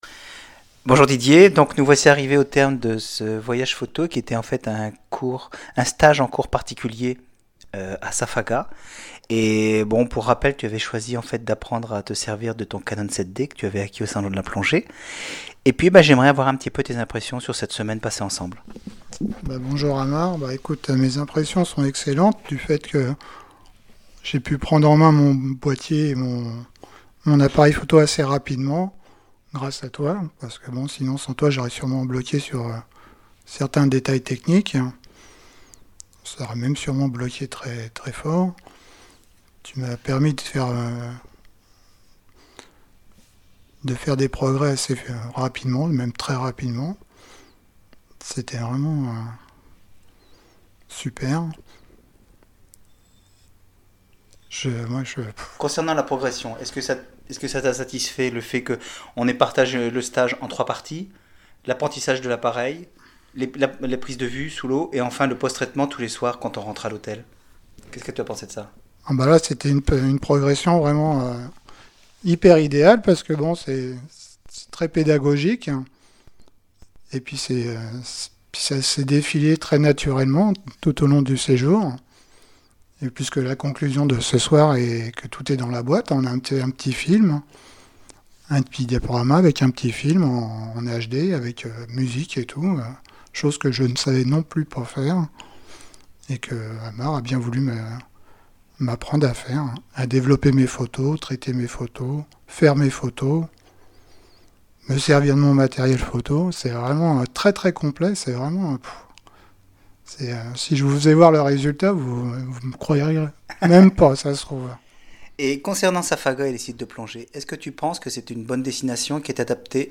Le commentaire audio